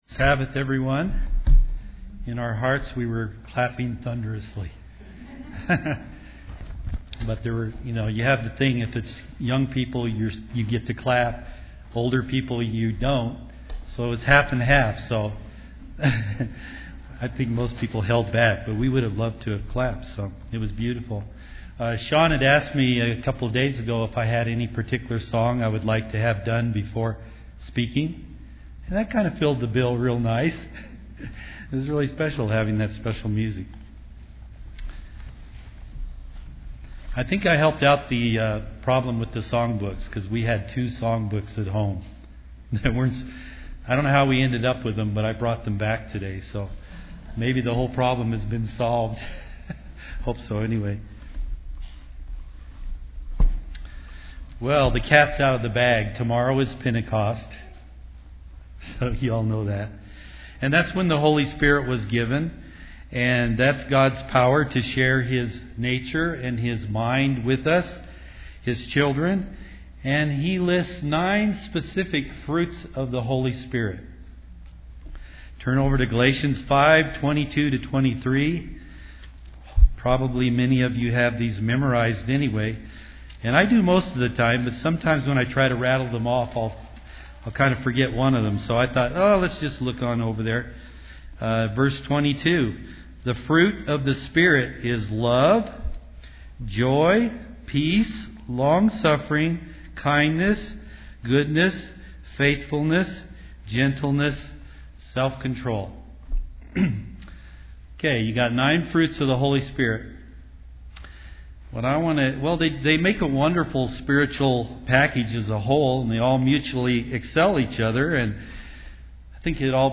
All 9 fruits mutually support each other, and Christians want to grow in all of them daily. This sermon will analyze what the Bible teaches about the sixth fruit that can seem a little nebulous compared to the others.